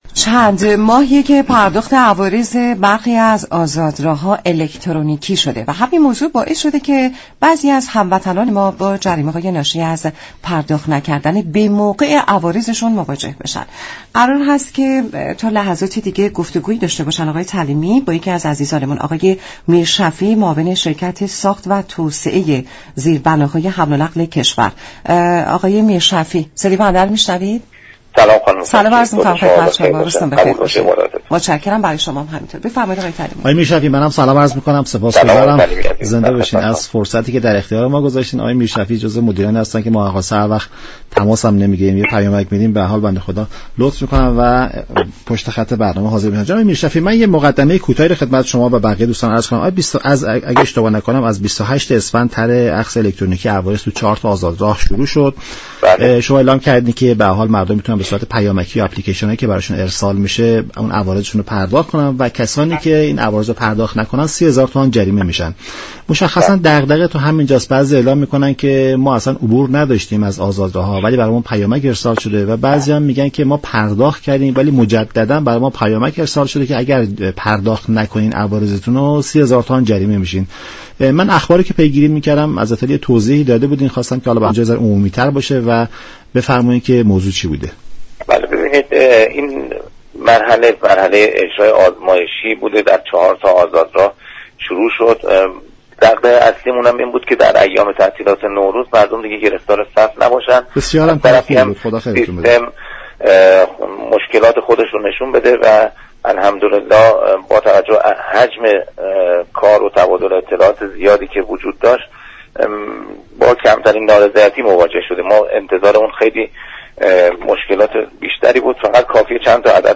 برنامه نمودار شنبه تا چهارشنبه هر هفته ساعت 10:20 از رادیو ایران پخش می شود. این گفت و گو را در ادامه باهم می شنویم.